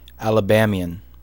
Ääntäminen
Synonyymit Alabaman Ääntäminen US Tuntematon aksentti: IPA : /ˌæləˈbæmi.ən/ Haettu sana löytyi näillä lähdekielillä: englanti Käännöksiä ei löytynyt valitulle kohdekielelle. Määritelmät Adjektiivi Pertaining to the American state Alabama , or its natives and residents.